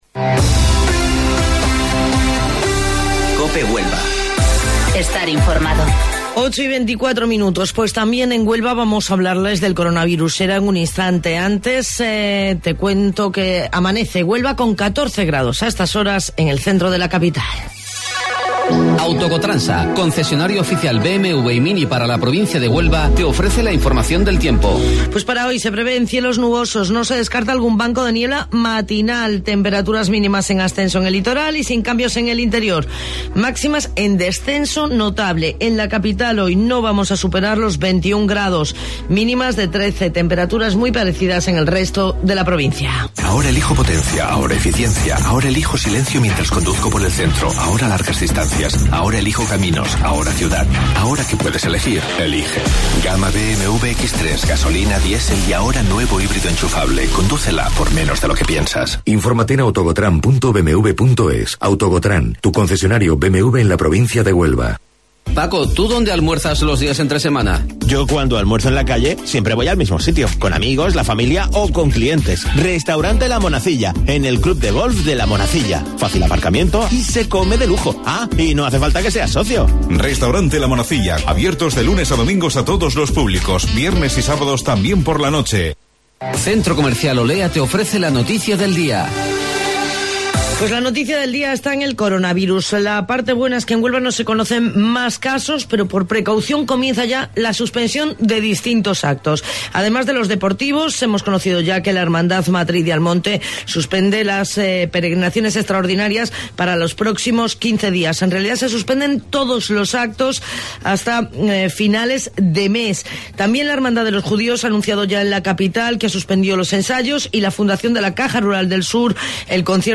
AUDIO: Informativo Local 08:25 del 12 Marzo